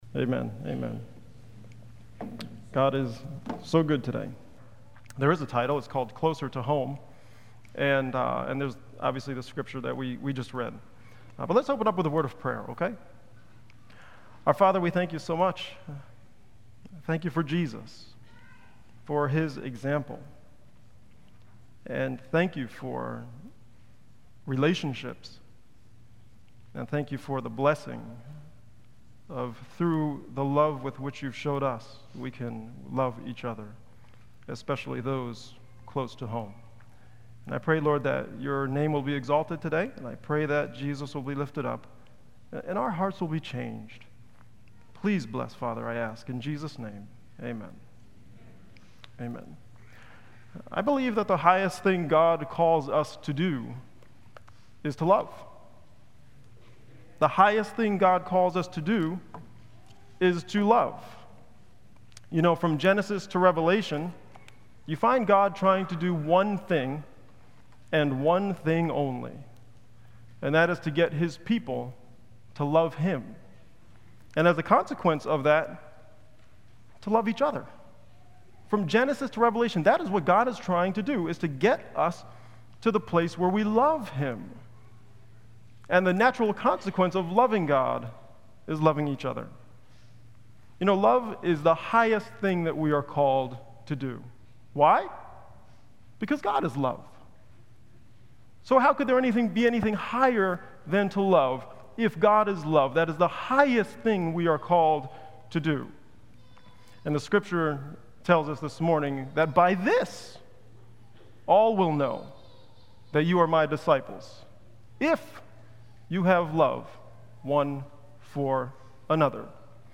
Sabbath Sermons